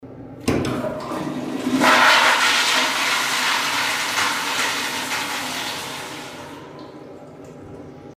Звуки унитаза
В общественном туалете смыв воды в унитазе